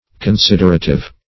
Search Result for " considerative" : The Collaborative International Dictionary of English v.0.48: Considerative \Con*sid"er*a*tive\, a. Considerate; careful; thoughtful.